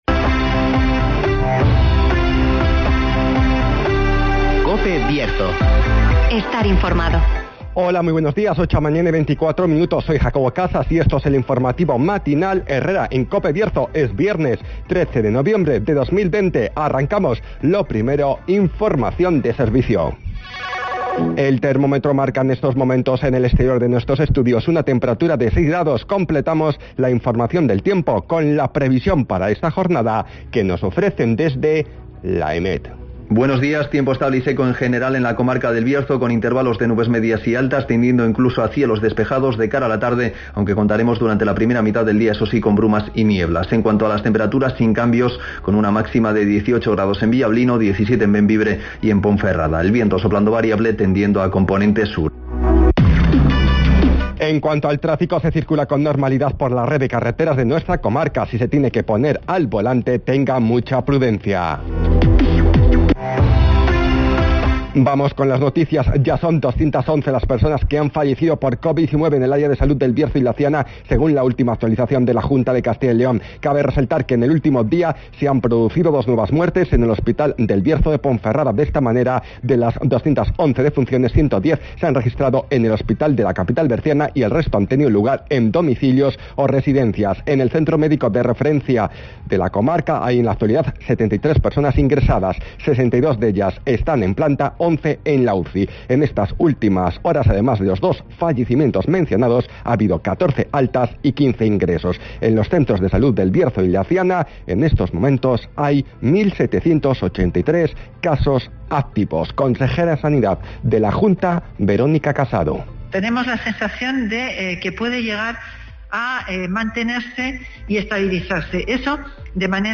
INFORMATIVOS
Repaso a la actualidad informativa del Bierzo. Escucha aquí las noticias de la comarca con las voces de los protagonistas.